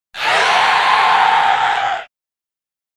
Download Alien sound effect for free.
Alien